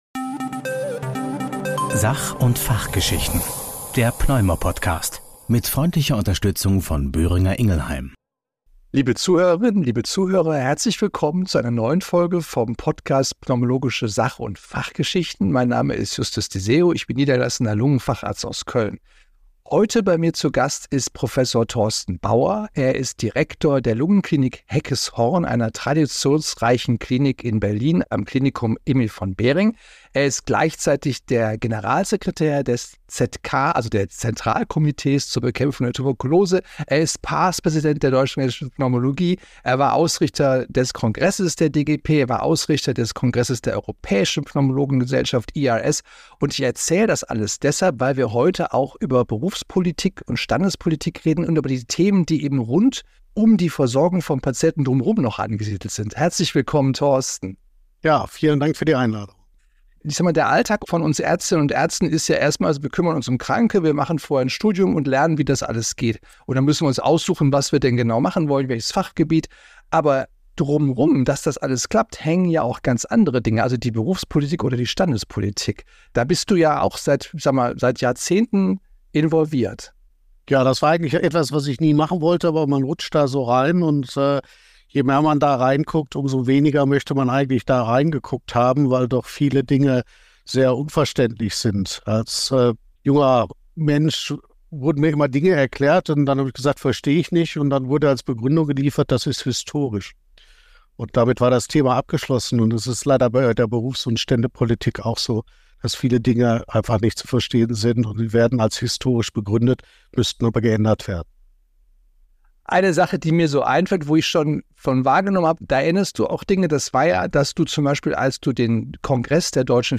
Die beiden Experten diskutieren offen über die finanzielle Realität im Krankenhaus- und Praxisalltag, wo Kostenerstattungssysteme und Verhandlungen mit Krankenkassen den ärztlichen Handlungsspielraum beeinflussen. Sie beleuchten die Schwierigkeiten der Zusammenarbeit zwischen ambulantem und stationärem Sektor und plädieren für mehr Transparenz und Verständnis in der Finanzierungsdebatte.